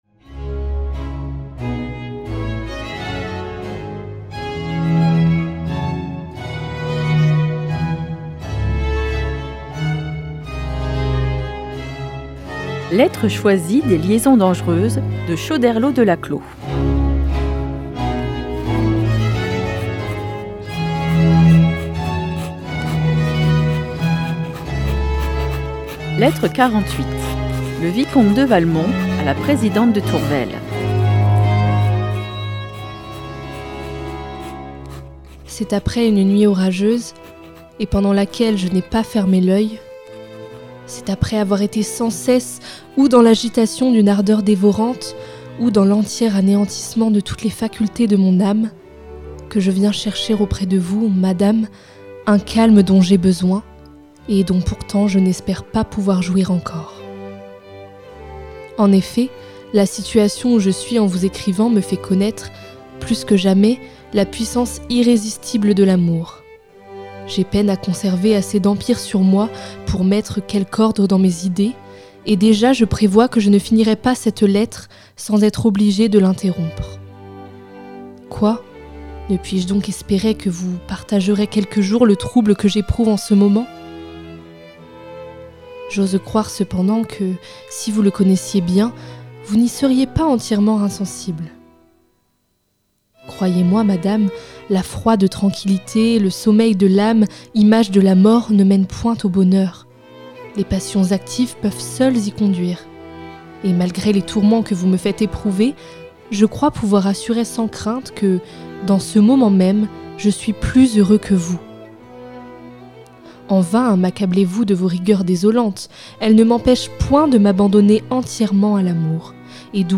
🎧 Lettres choisies des Liaisons Dangereuses - Les ateliers de fictions radiophoniques de Radio Primitive
classé dans : fiction